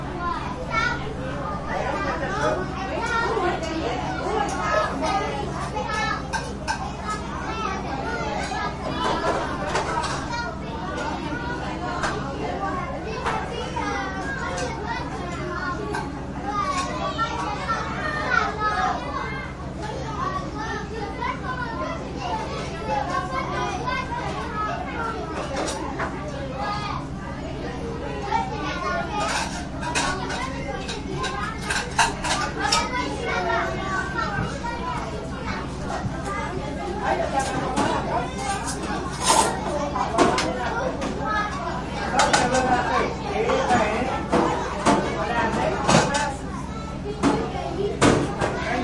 蒙特利尔 " 人群中的小学或幼儿园的孩子们，法国儿童魁北克的午餐时间，厨房里的冰箱里的哼哼。
描述：人群int小学或幼儿园儿童法国enfants quebecois午餐时间厨房冰箱嗡嗡声
Tag: 厨房 儿童 儿童组织 魁北克 小学 幼儿园 法国 INT 人群 孩子们 学校 沃拉